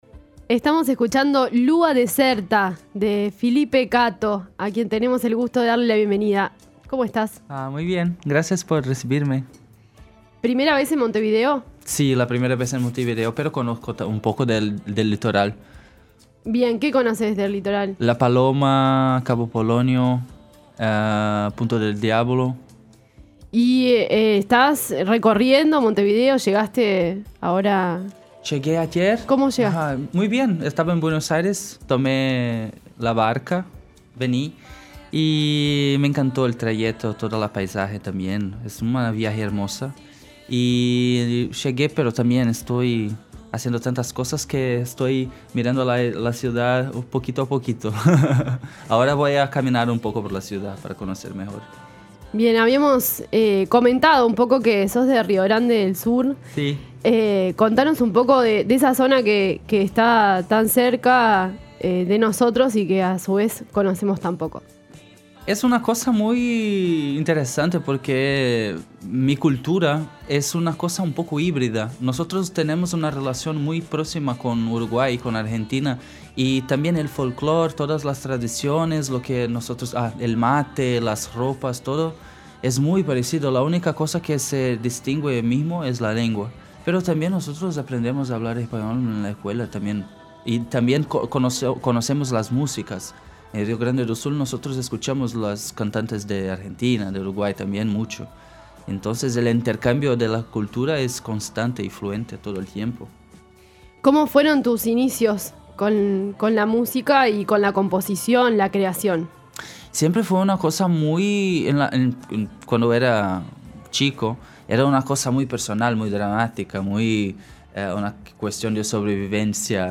Este lunes estuvo en La Trama, contando sus inicios y su estilo musical, al que definió como «mutante». Además habló de sus referentes y de la situación compleja que atraviesa Brasil, donde considera que formar parte de la comunidad LGBTI y exponerse ya es «un acto de resistencia».